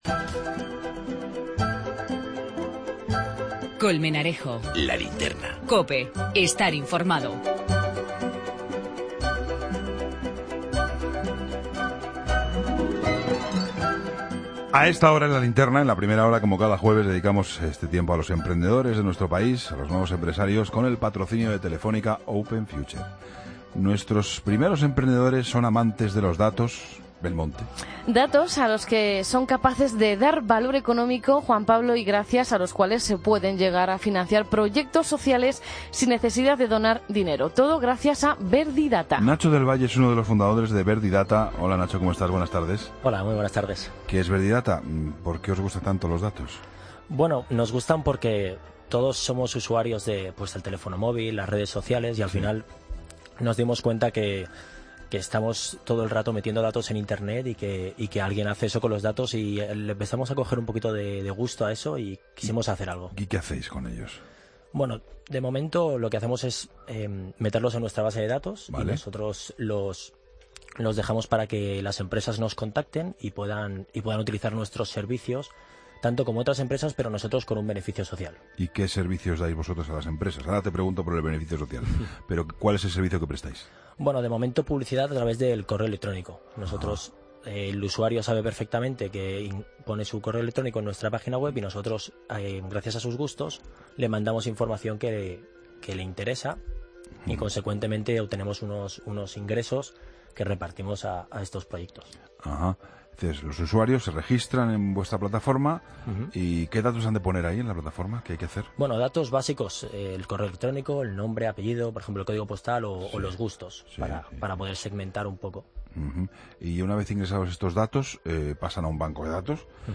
entrevistan